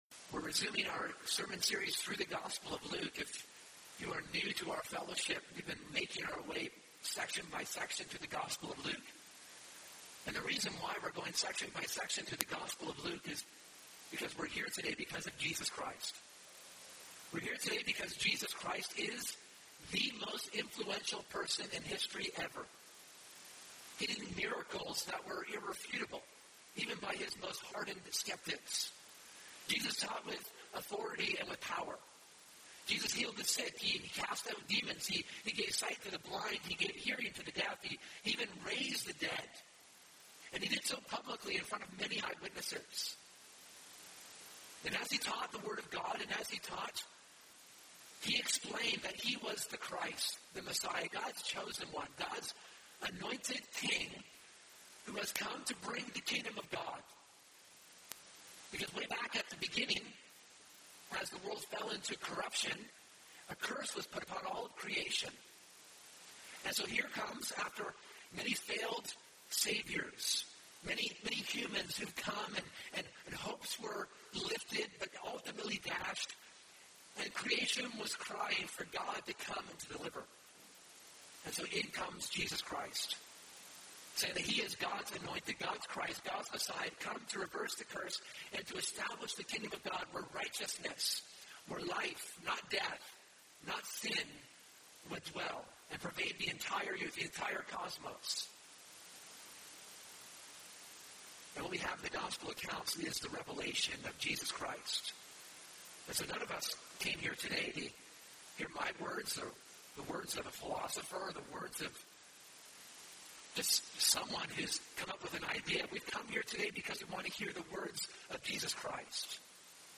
In this sermon, we’re reminded to be loyal to Jesus Christ in amid opposition. When there is public pressure to be privatize our faith, there must be a public profession of allegiance to Jesus Christ…